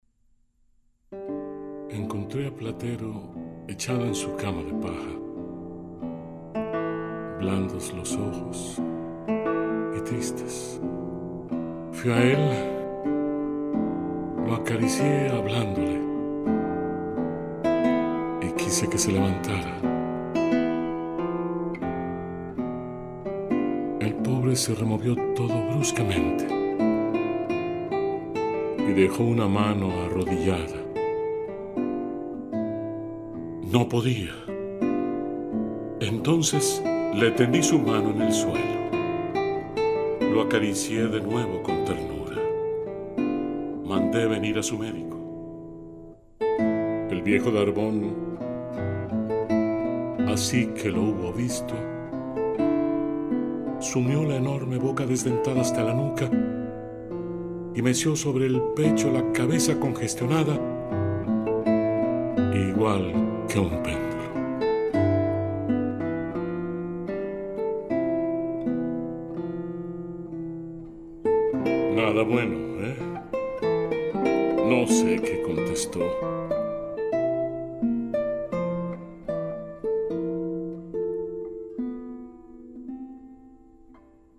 Narrator
Guitar